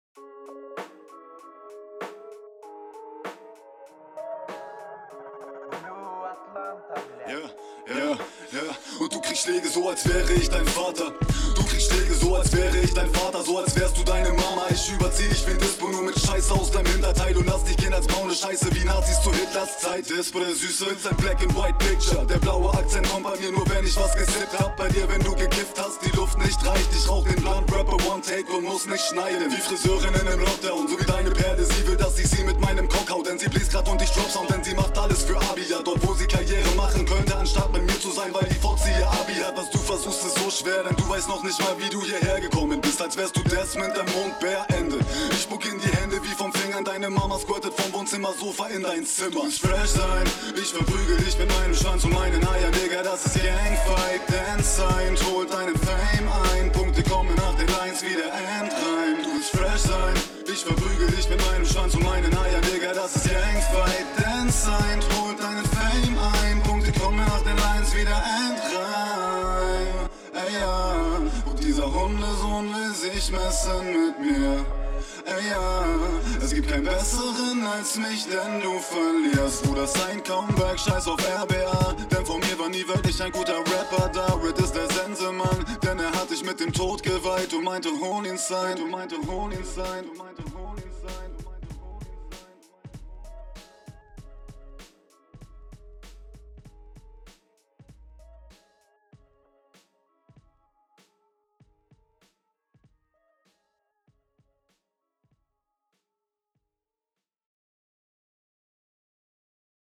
Stimmeinsatz kommt schön durchdringend auf den Beat! Die Mische is hart basslos irgendwie, das irritiert …
Flow ziemlich gut, mische auch.